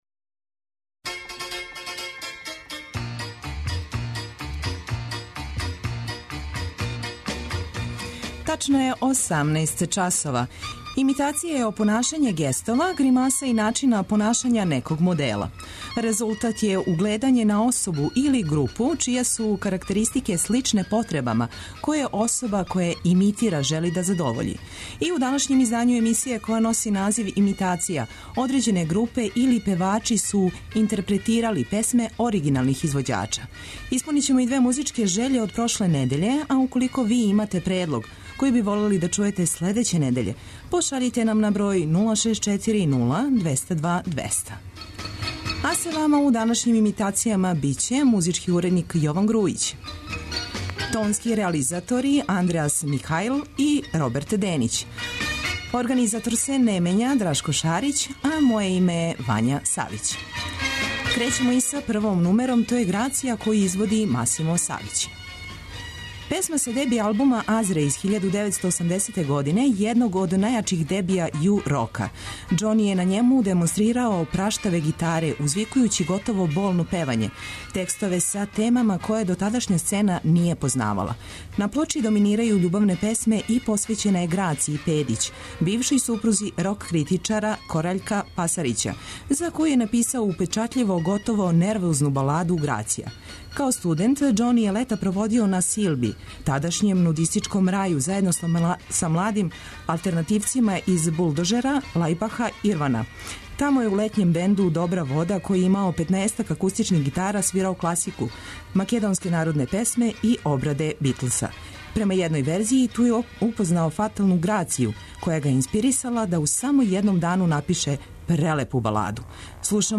Имитација је емисија у којој се емитују обраде познатих хитова домаће и иностране музике.